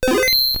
Save.wav